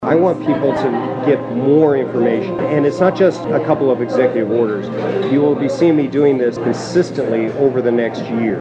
Below are some of the soundbites from the media sit down.